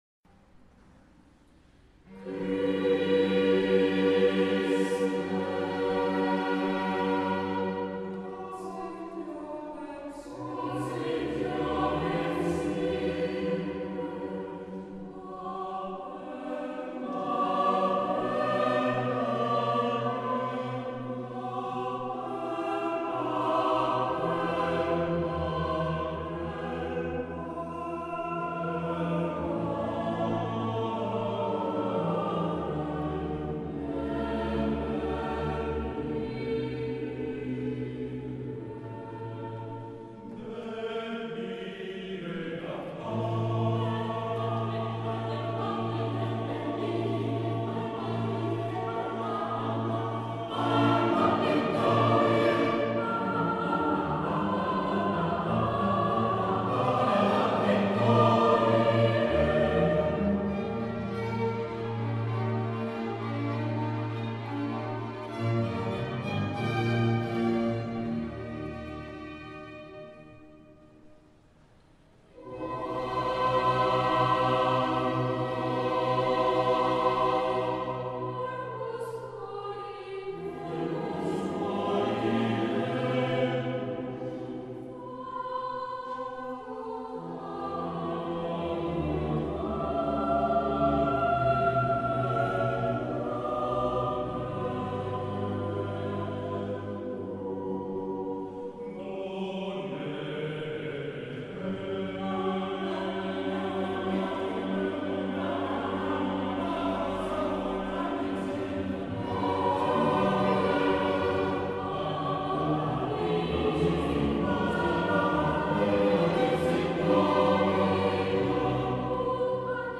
Choir Music